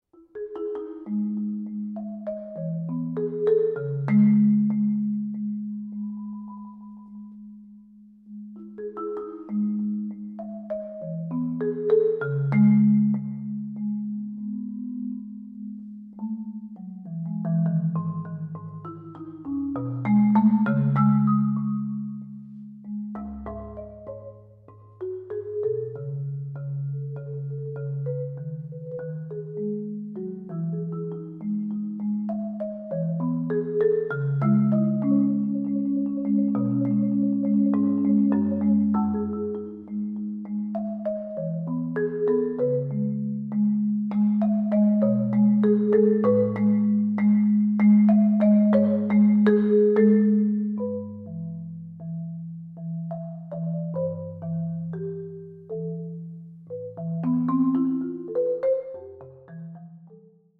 for Solo Marimba